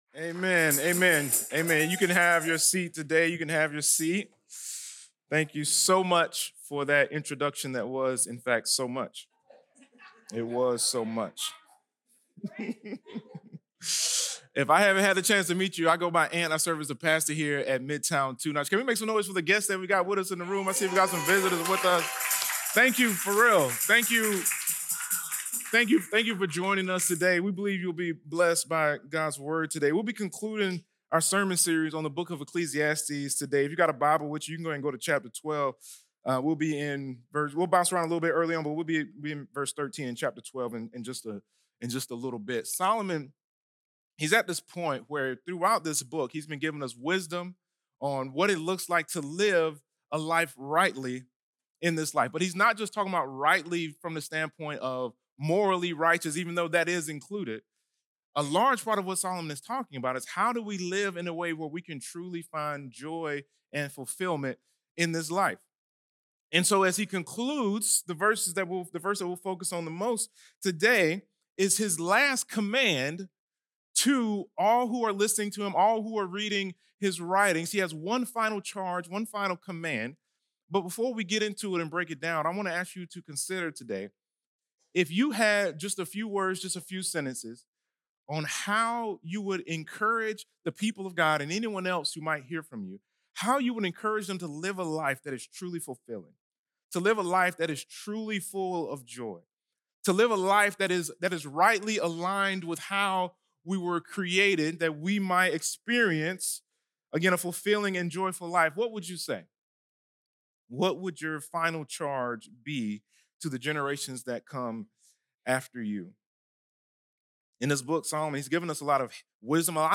Teaching from Midtown Fellowship's Two Notch church in Columbia, SC.